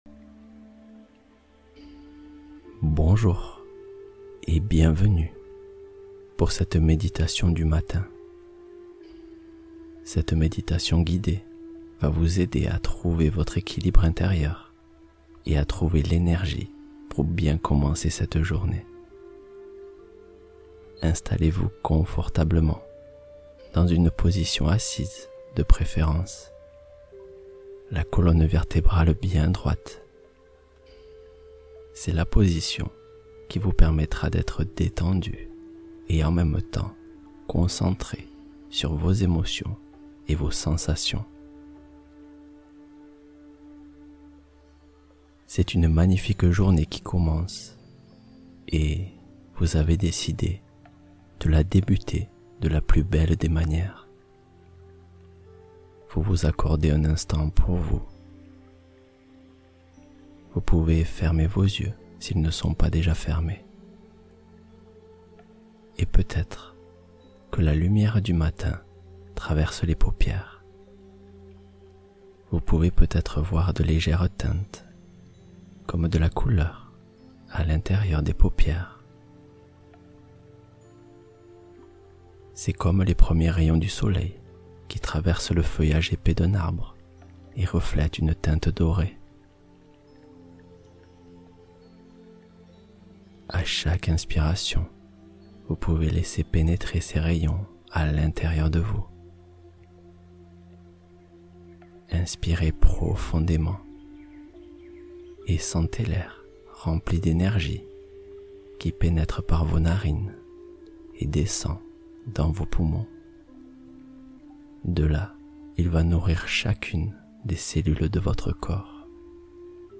Se laisser aller : méditation vers un calme profond